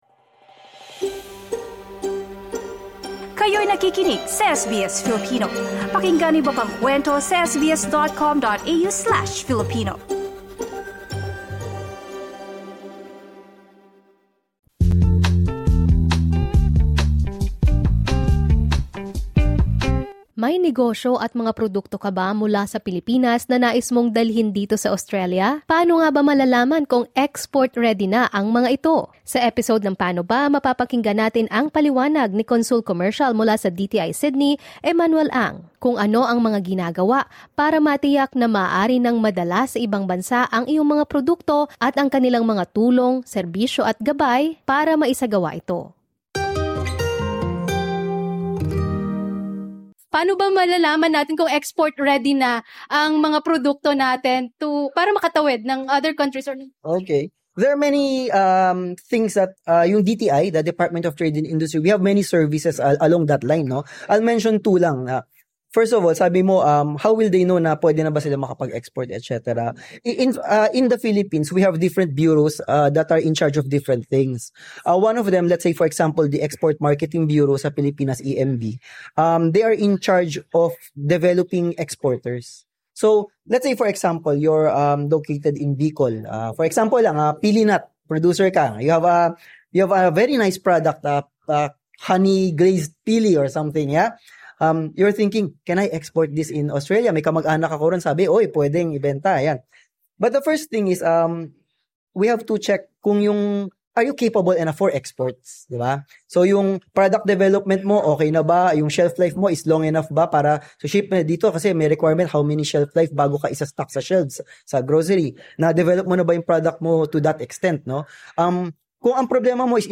Commercial Consul Emmanuel Ang from the Department of Trade and Industry – Philippine Trade and Investment Center in Sydney outlined the essential steps for small and medium enterprises (SMEs) preparing to enter the Australian market.